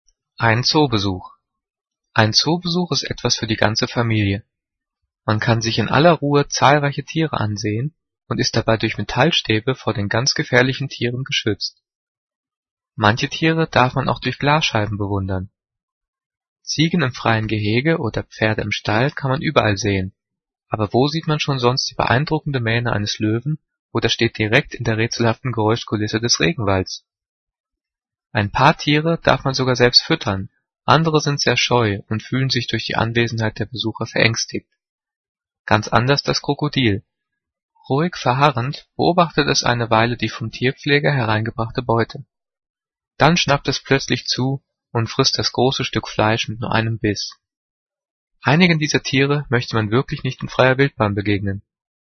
Gelesen:
gelesen-ein-zoobesuch.mp3